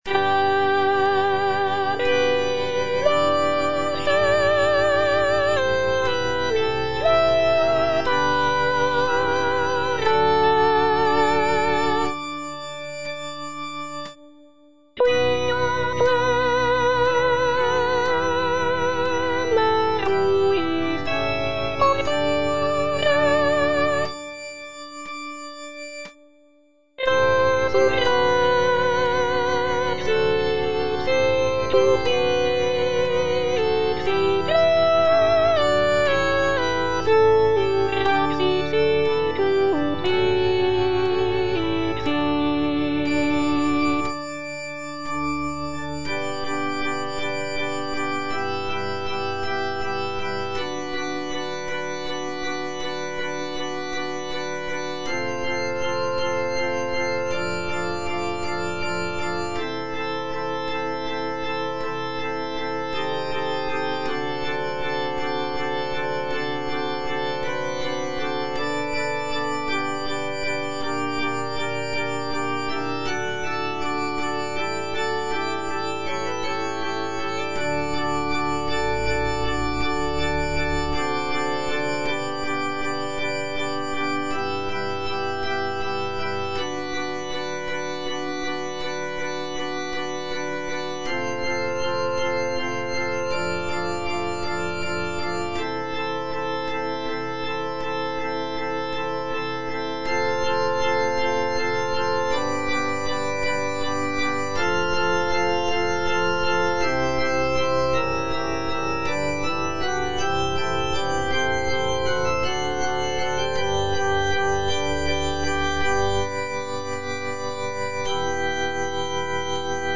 P. MASCAGNI - REGINA COELI DA "CAVALLERIA RUSTICANA" Internal choir, soprano I (Voice with metronome) Ads stop: